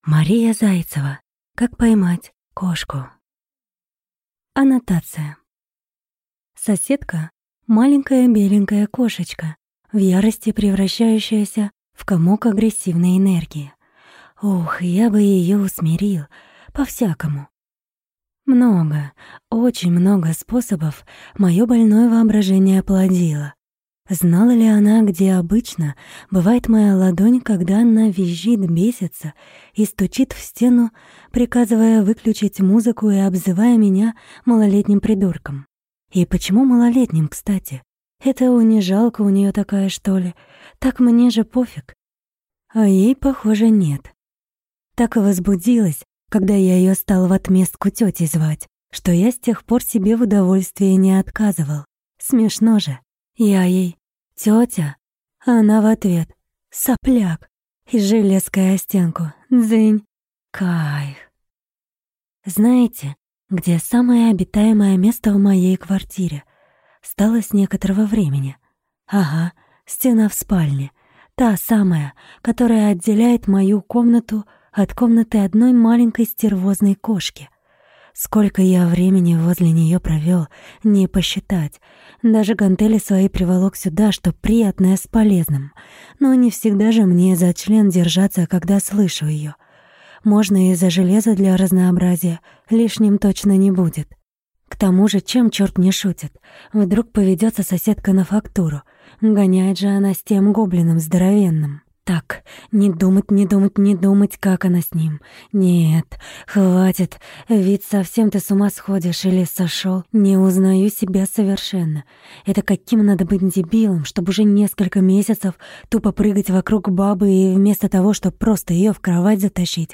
Аудиокнига Как поймать кошку | Библиотека аудиокниг